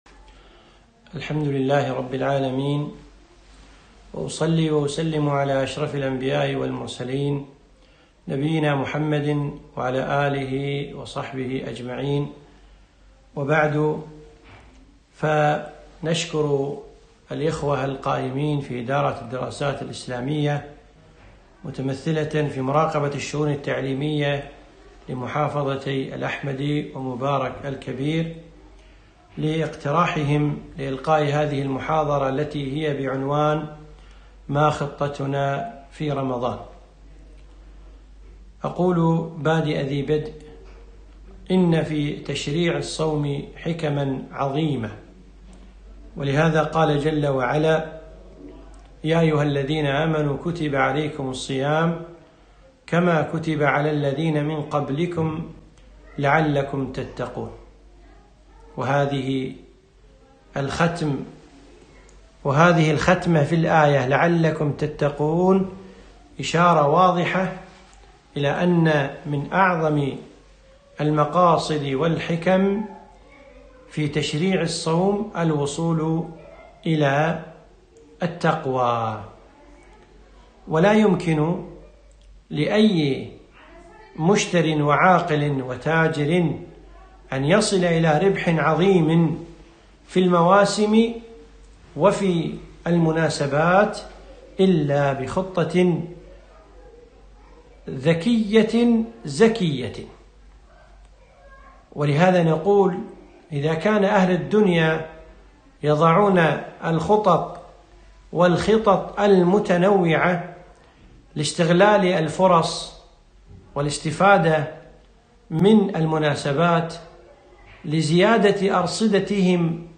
محاضرة رائعة - ما خطتنا في رمضان؟